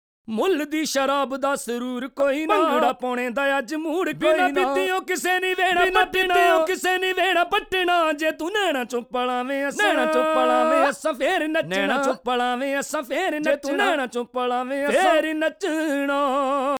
Key C# Bpm 86